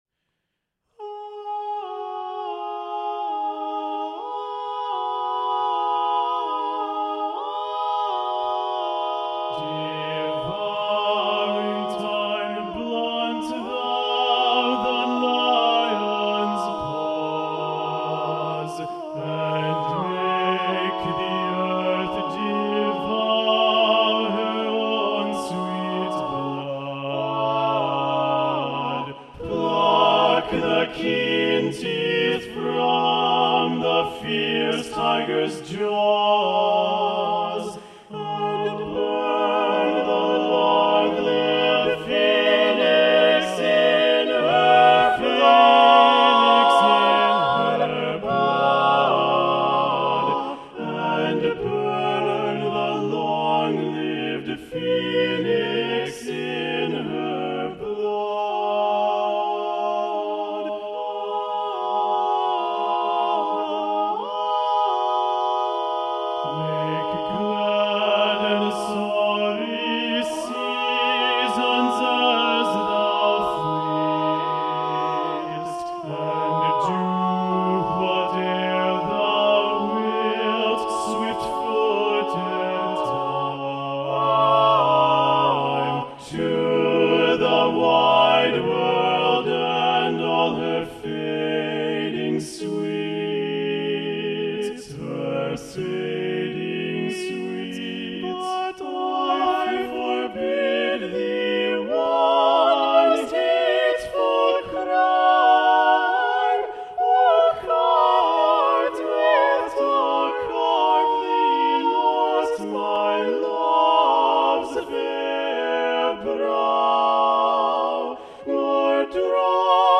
Choral Music
Choral ~ General ~ A Cappella
SCORING:  SATB a cappella (frequent divisi)